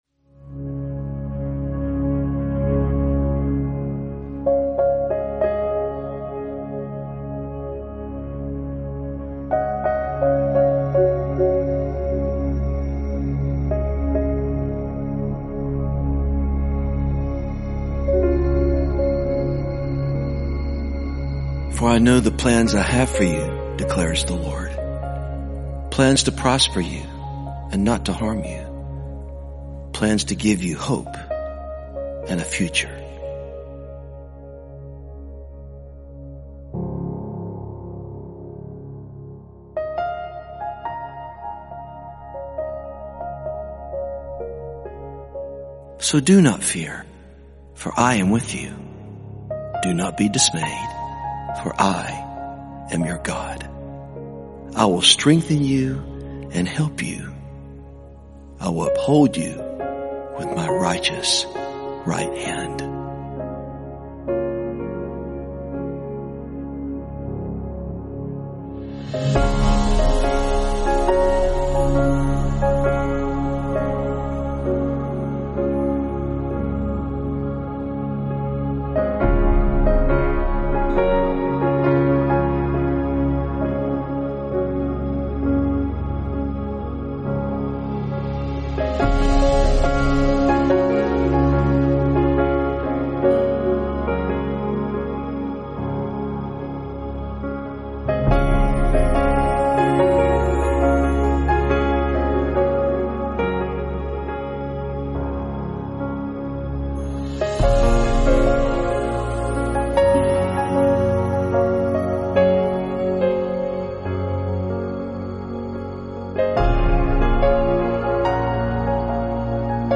calming, introspective, and soothing instrumental music